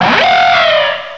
sovereignx/sound/direct_sound_samples/cries/archeops.aif at master